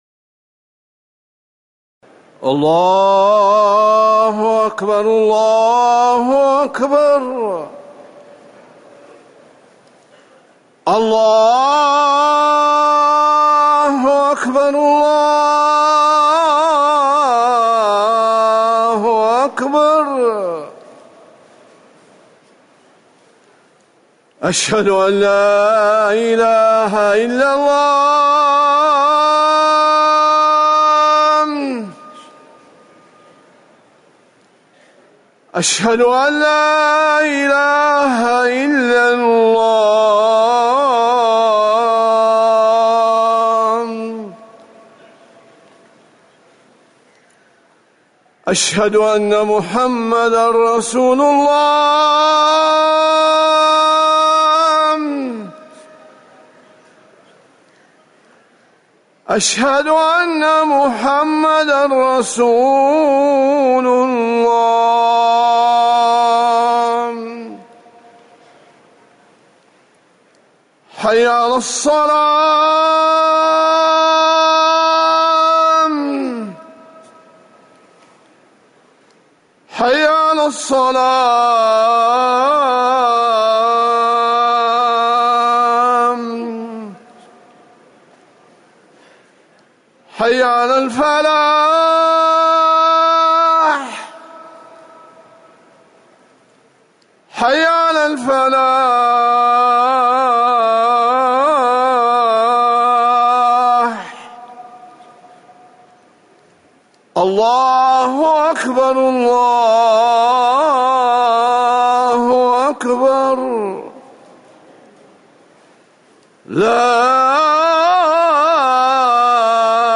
أذان المغرب - الموقع الرسمي لرئاسة الشؤون الدينية بالمسجد النبوي والمسجد الحرام
تاريخ النشر ١١ محرم ١٤٤١ هـ المكان: المسجد النبوي الشيخ